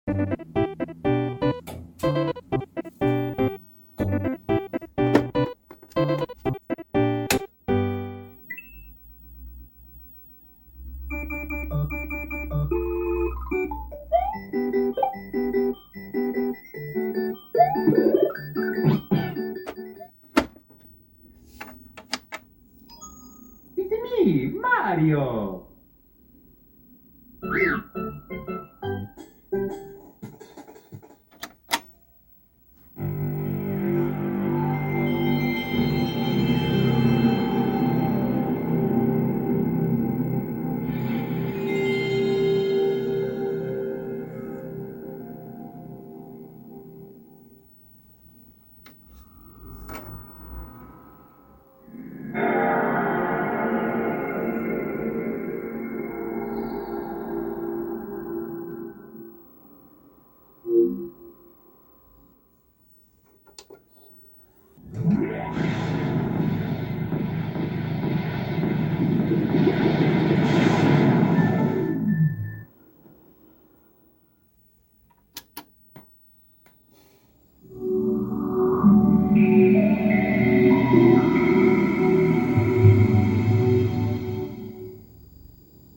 Powering up each of my old school consoles!